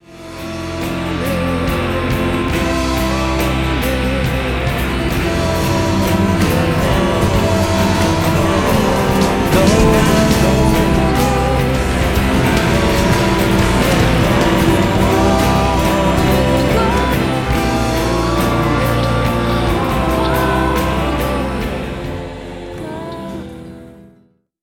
Songs I – V is a series of short sound pieces edited from sung instances of the word ‘golden’, to produce occasional audio interludes, at once plaintive, joyful and wistful.